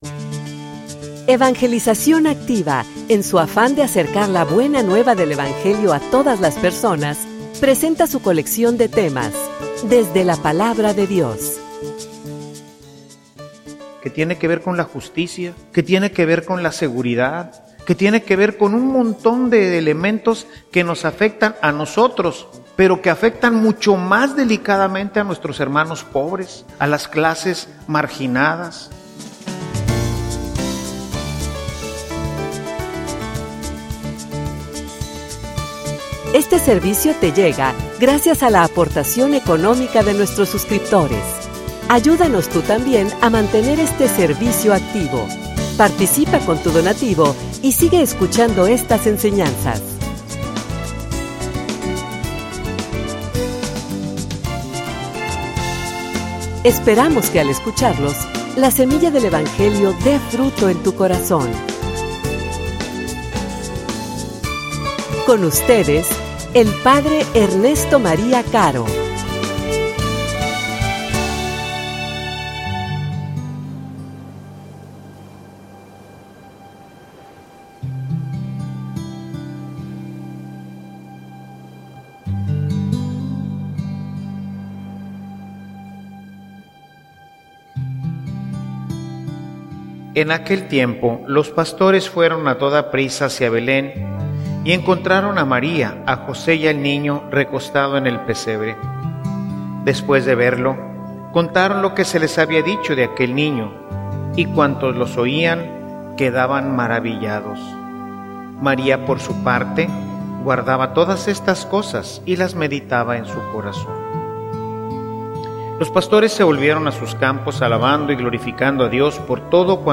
homilia_Maria_modelo_para_la_meditacion_cristiana.mp3